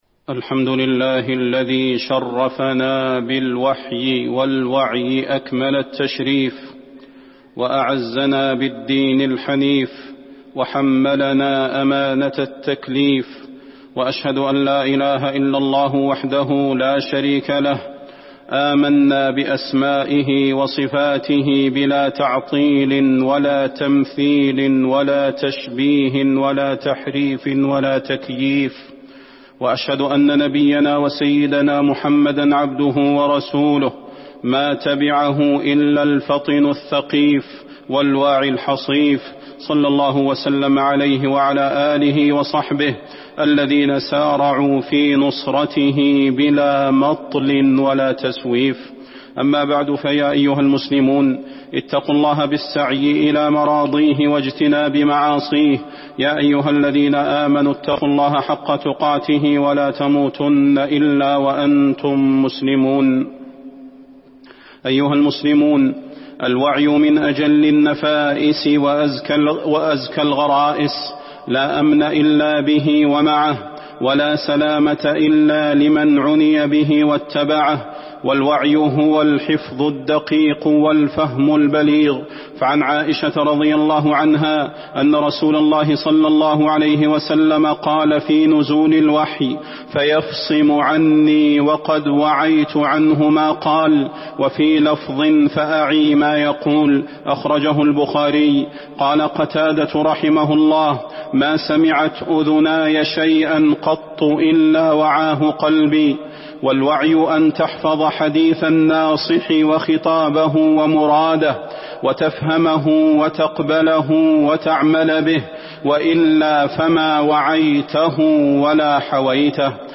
فضيلة الشيخ د. صلاح بن محمد البدير
تاريخ النشر ٢٠ شوال ١٤٤١ هـ المكان: المسجد النبوي الشيخ: فضيلة الشيخ د. صلاح بن محمد البدير فضيلة الشيخ د. صلاح بن محمد البدير حاجتنا إلى الوعي